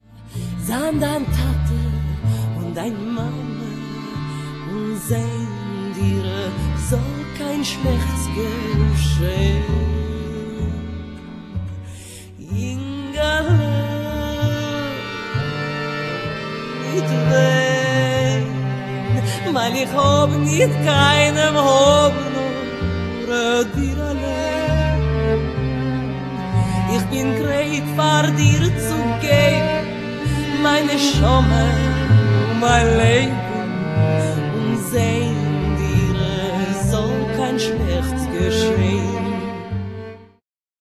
recital piosenek jidysz
śpiew, gitara
klarnet, tarogato
skrzypce
wiolonczela
kontrabas
akordeon
skrzypce, mandolina